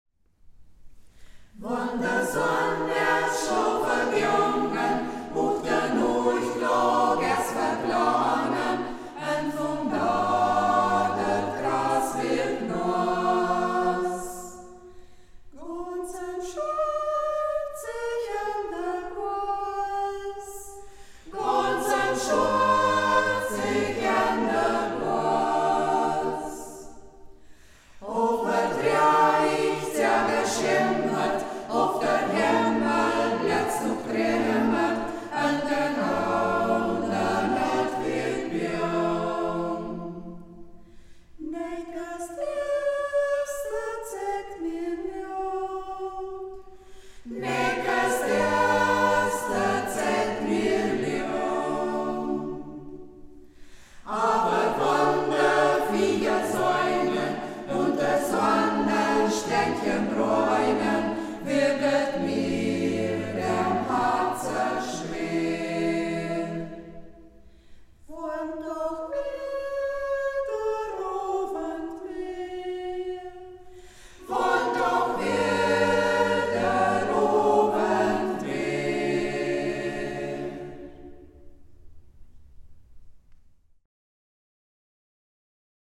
Otto Piringer (Text), Martin Kutschis (Melodie)
Singkreis Kampestweinkel • Ortsmundart: Braller • 1:25 Minuten • Herunterladen
104_wonn_de_sann_aes_schlofe_gongen_braller_singkreis_kampestweinkel.mp3